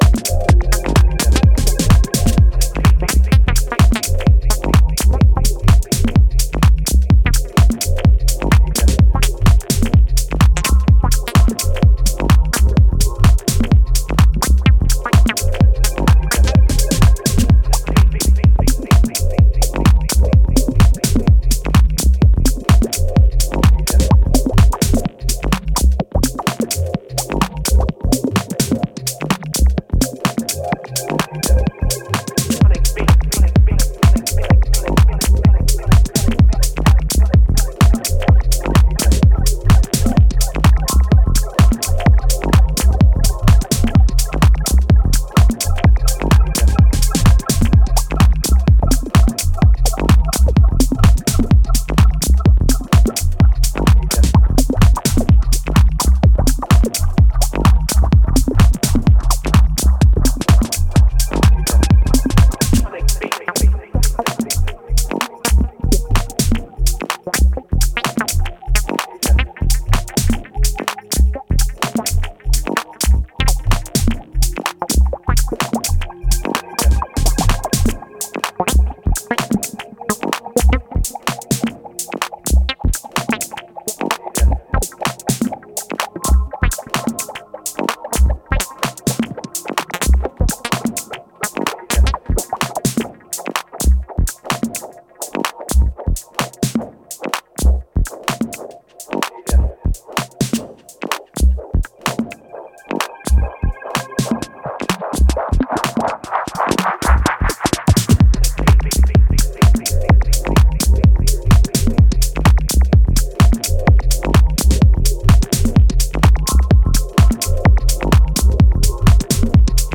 Inspired by the early white label house music releases.